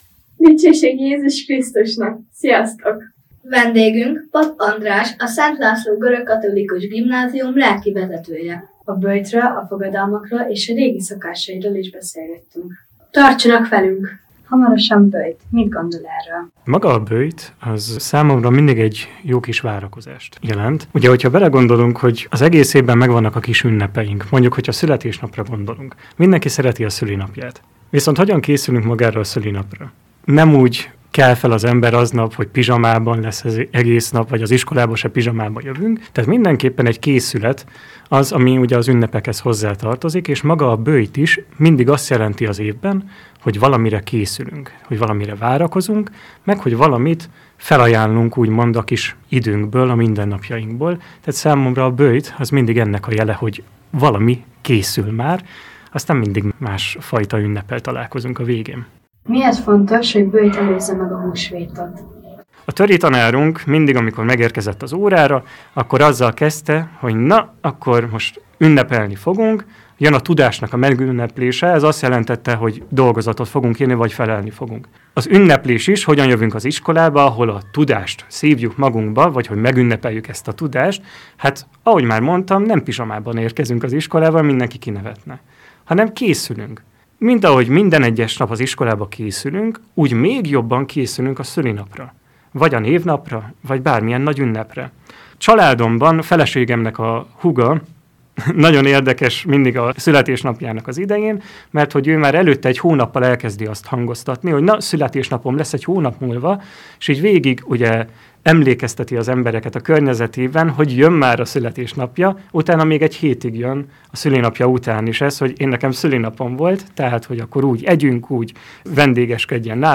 A böjtről – Interjú